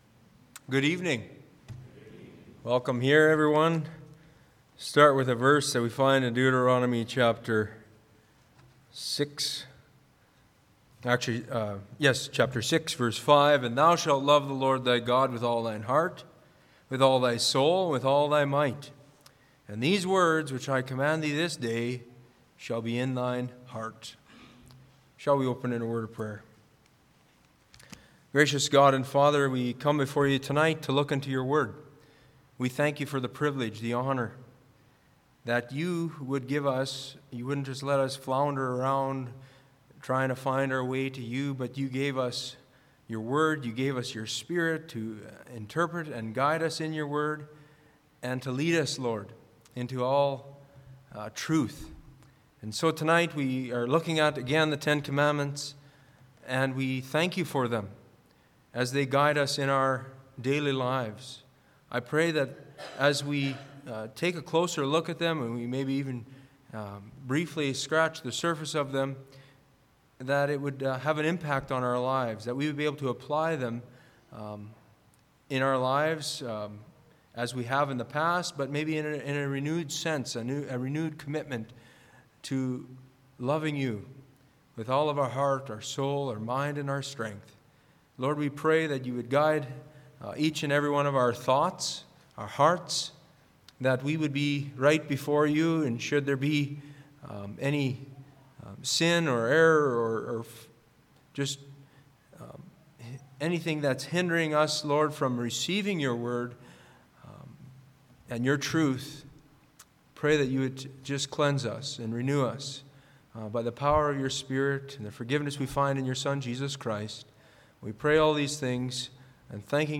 Church Bible Study